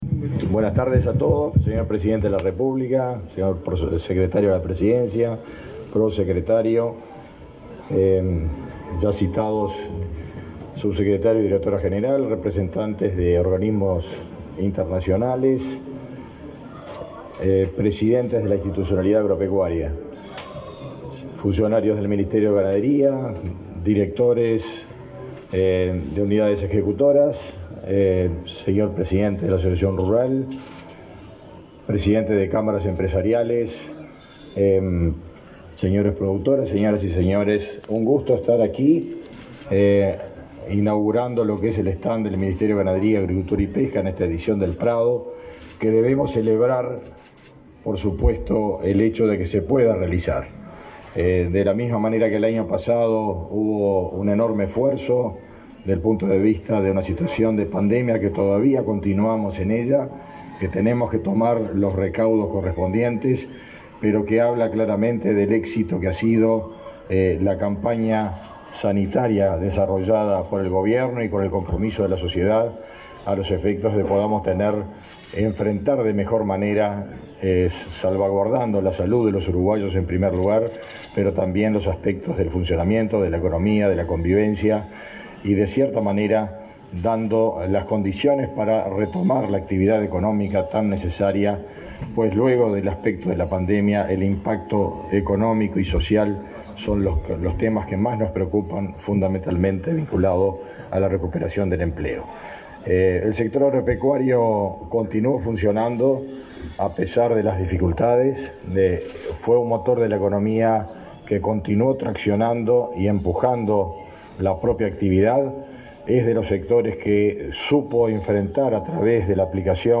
Declaraciones del ministro de Ganadería, Agricultura y Pesca, Fernando Mattos
El Ministerio de Ganadería inauguró stand en la Rural del Prado este 10 de setiembre. El ministro Mattos disertó en el evento.
Mattos oratoria.mp3